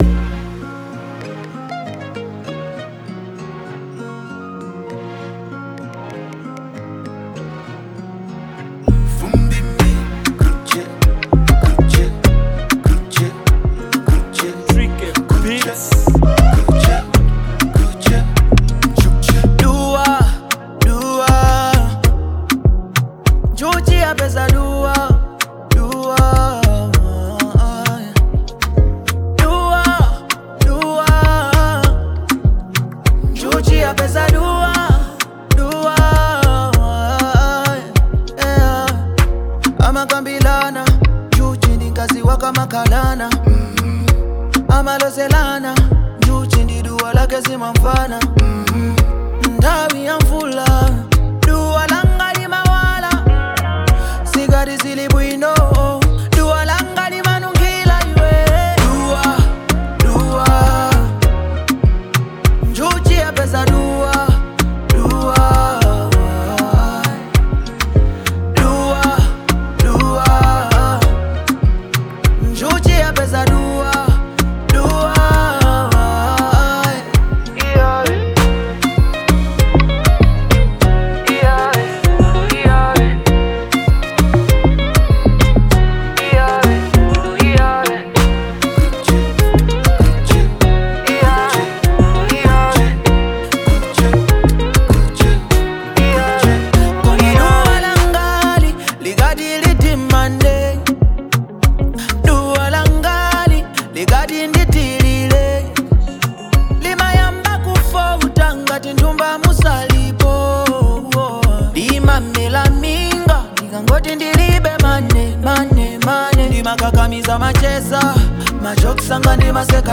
Genre : Afro Dancehall
afro dancehall song